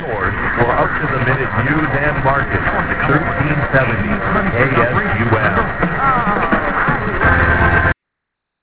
KSUM station ID